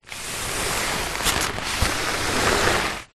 Звуки ковра: как звучит укладка на пол